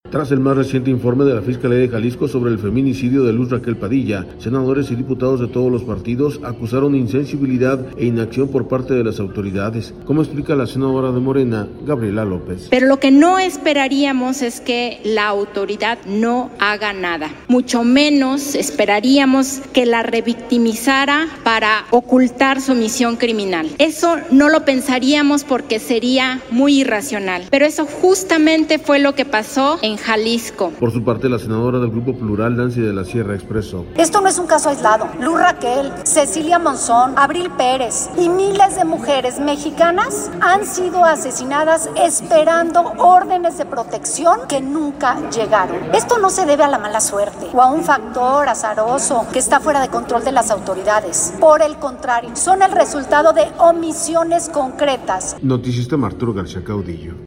Por su parte, la senadora del Grupo Plural, Nancy de la Sierra, expresó.